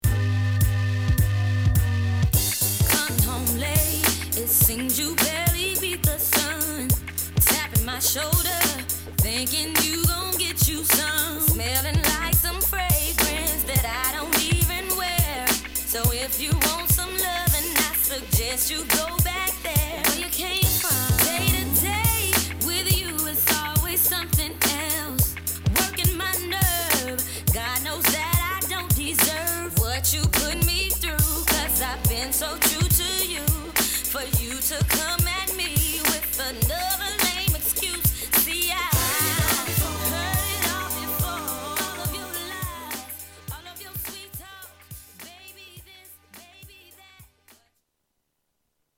soulful hit single
R&B